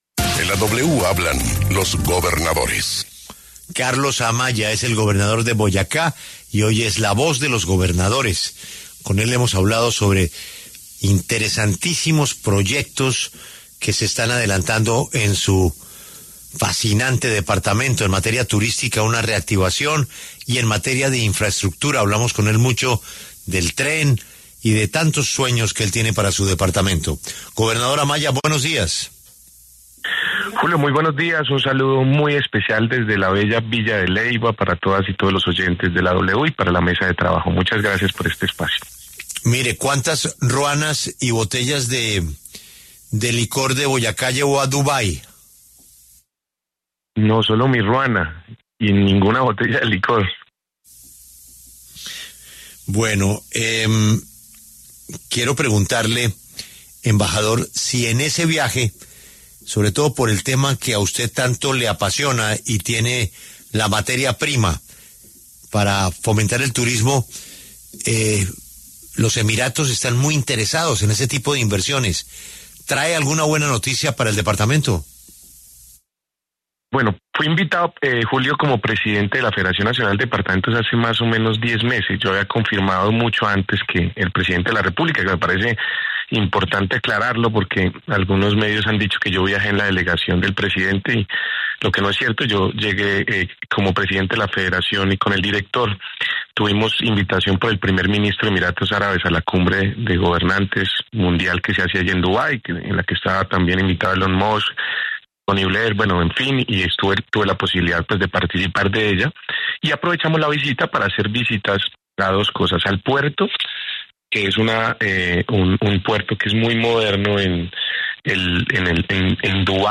Carlos Amaya, gobernador de Boyacá, habló en La W desde la Cumbre de gobernadores en Villa de Leyva sobre el reciente nombramiento de Antonio Sanguino en el Ministerio de Trabajo.